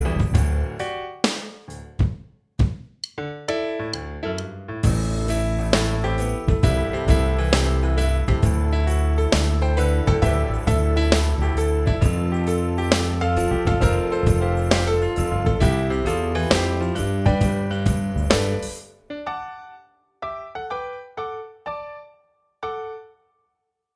Minus All Guitars Pop (1990s) 5:06 Buy £1.50